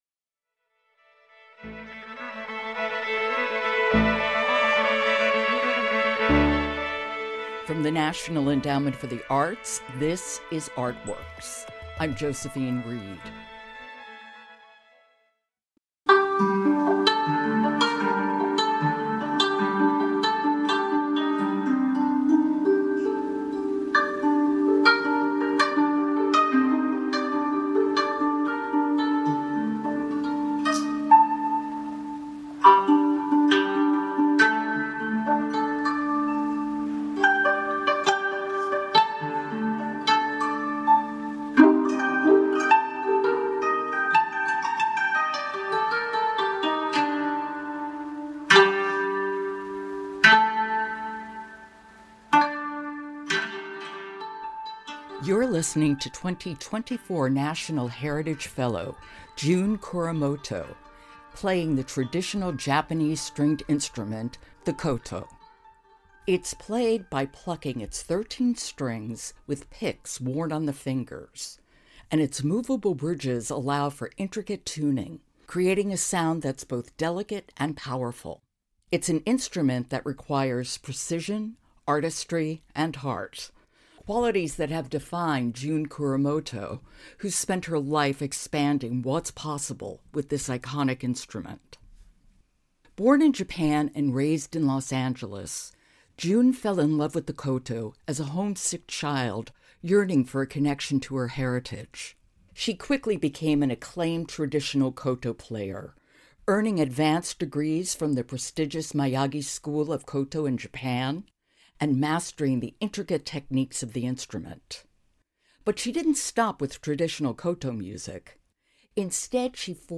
A conversation with 2024 NEA National Heritage Fellow and koto musician June Kuramoto. Kuramoto discusses tradition, innovation, and a lifetime of music.